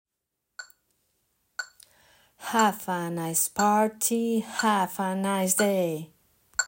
Rhythm and words
They contain phrases pronounced imitating the scores on the second column.